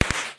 firework6.mp3